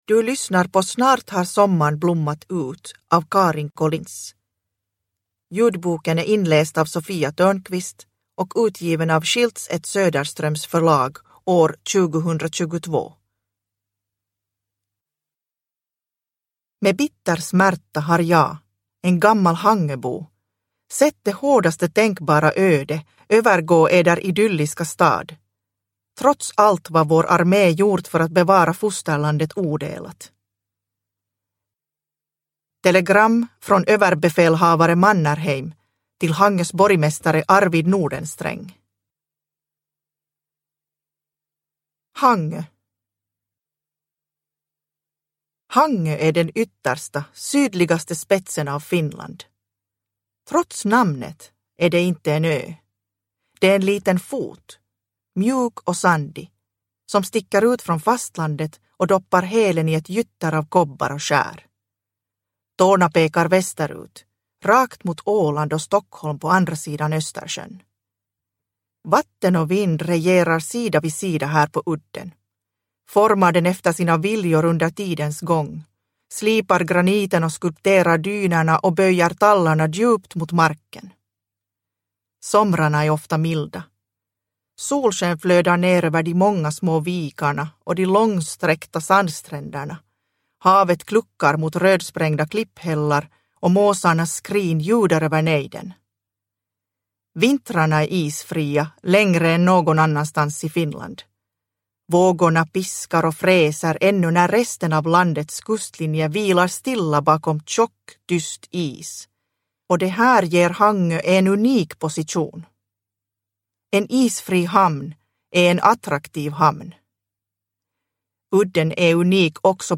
Snart har sommarn blommat ut – Ljudbok – Laddas ner